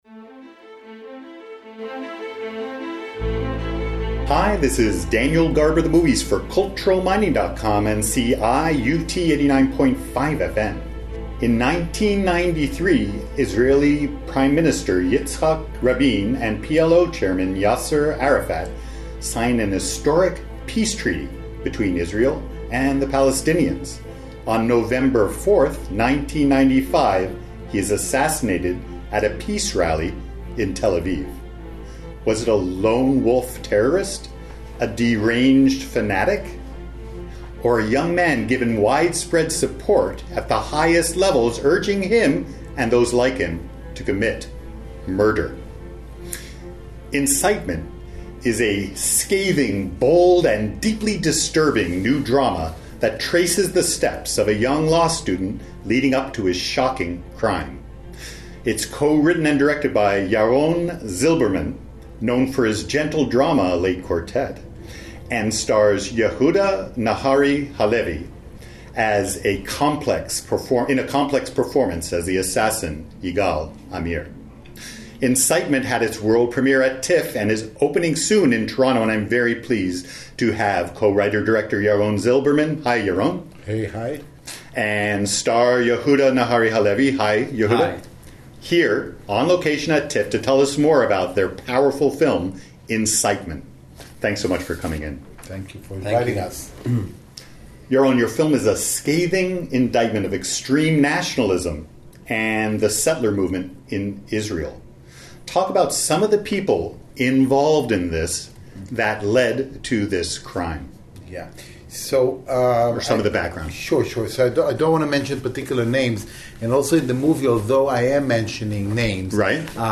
on location at TIFF.